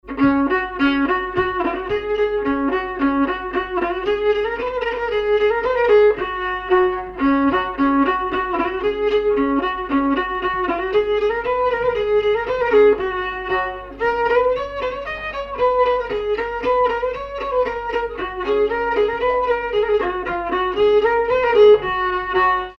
danse : marche
circonstance : bal, dancerie
Pièce musicale inédite